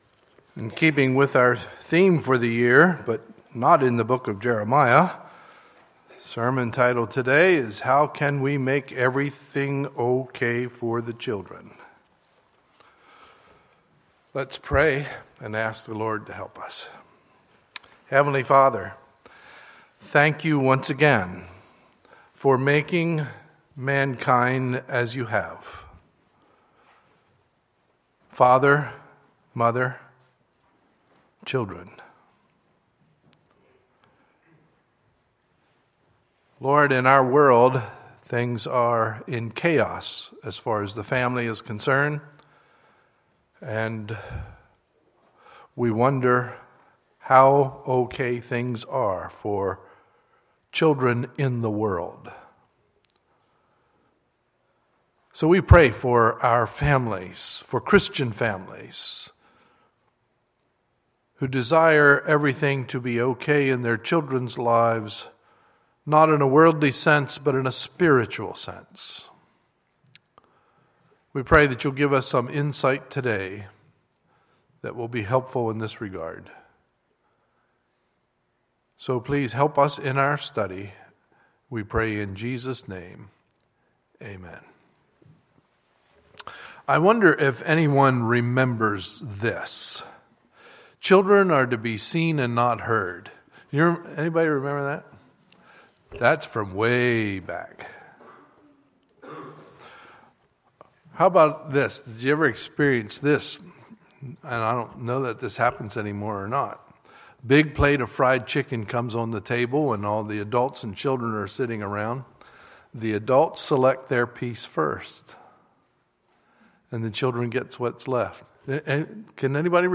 Sunday, May 17, 2015 – Sunday Morning Service